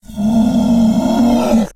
varan1.ogg